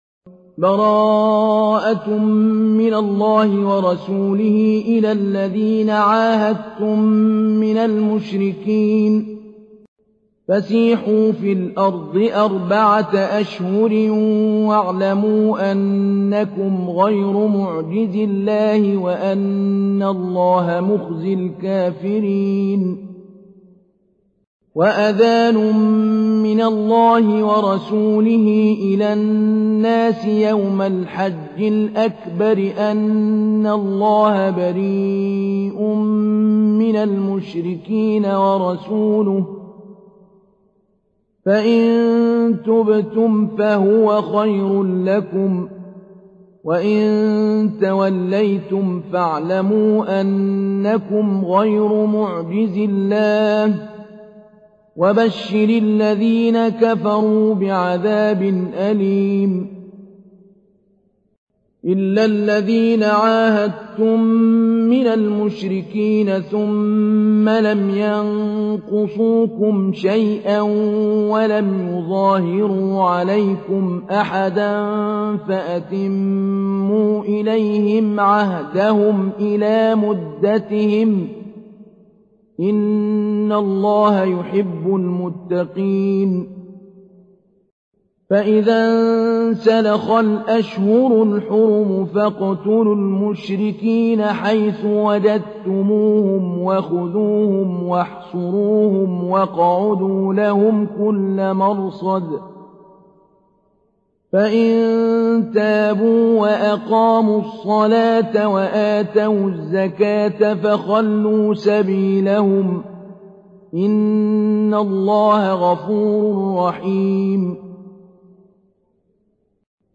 تحميل : 9. سورة التوبة / القارئ محمود علي البنا / القرآن الكريم / موقع يا حسين